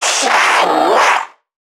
NPC_Creatures_Vocalisations_Infected [32].wav